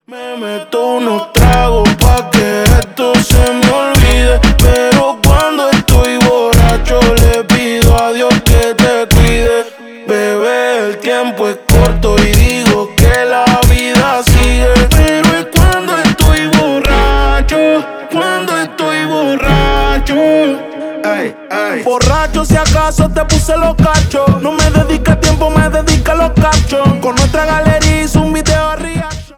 латинские